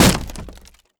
WoodBreak.wav